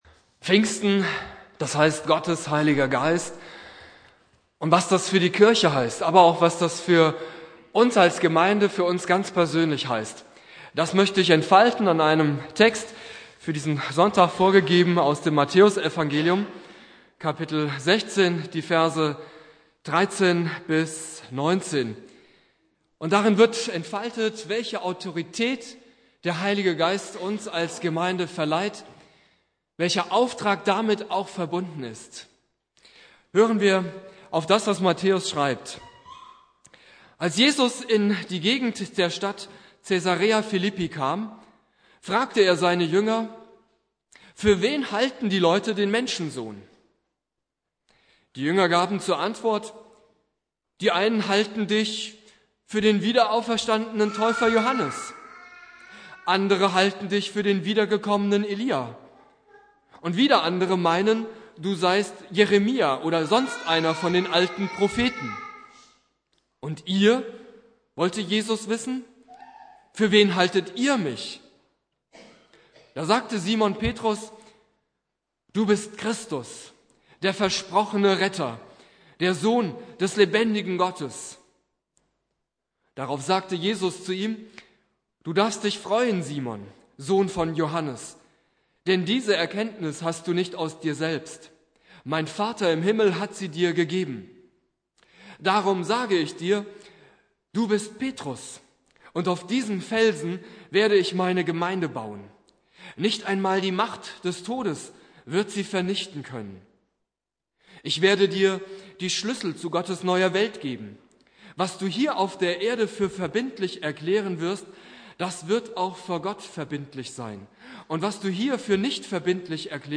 Predigt
Pfingstmontag Prediger